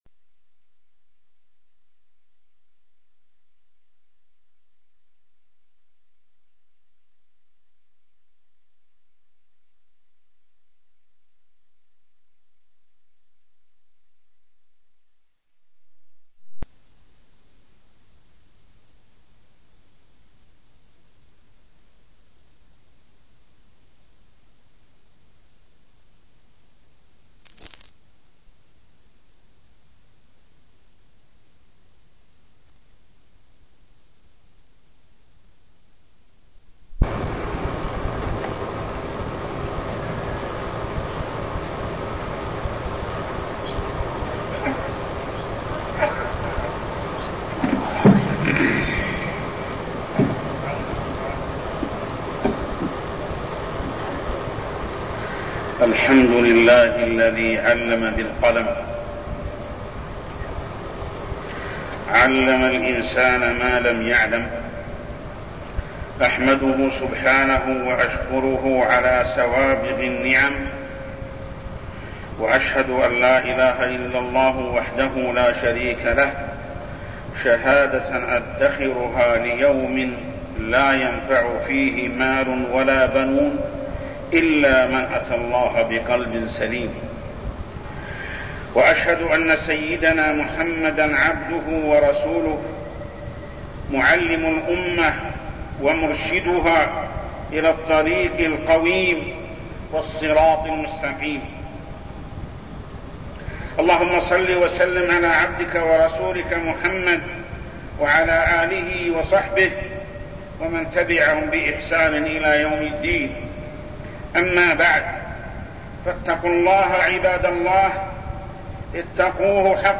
تاريخ النشر ١٨ ربيع الثاني ١٤١٥ هـ المكان: المسجد الحرام الشيخ: محمد بن عبد الله السبيل محمد بن عبد الله السبيل العلم والعمل